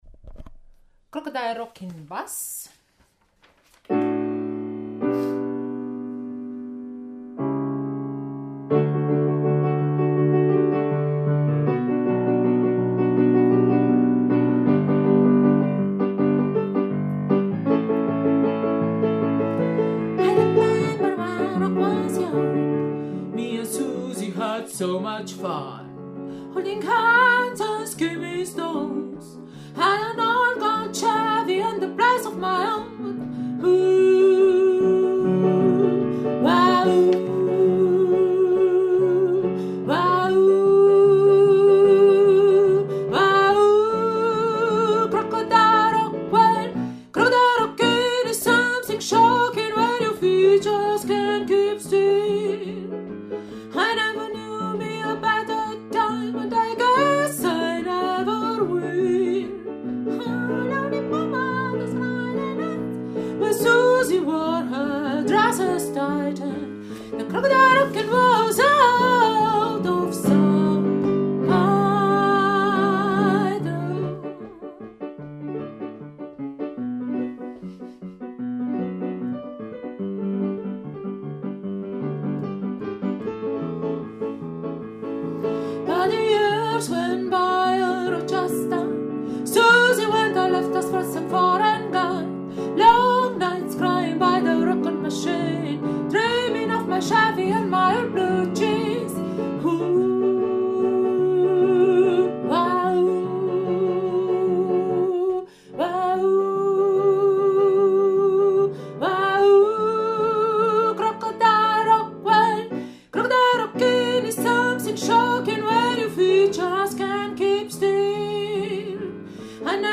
Crocodile Rock – Bass
Crocodile-Rock-Bass.mp3